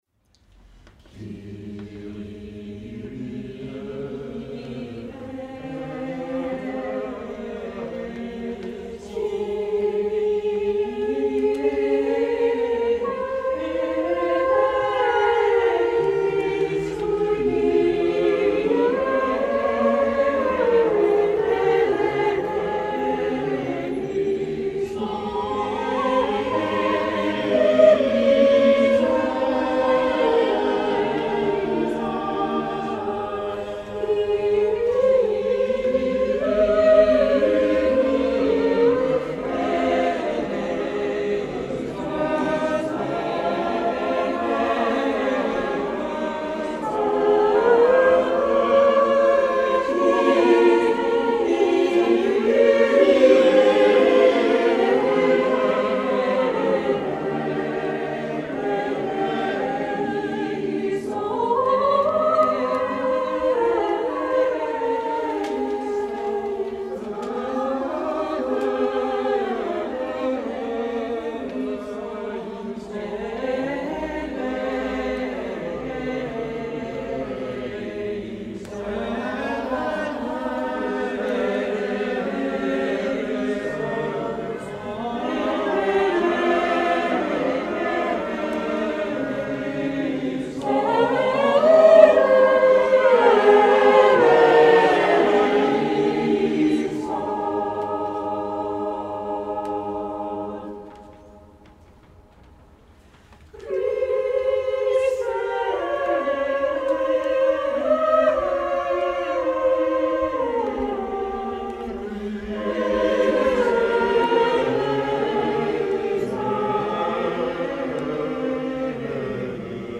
performed at the annual Loft Concert